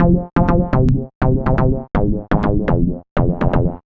Jockin Stylee F 123.wav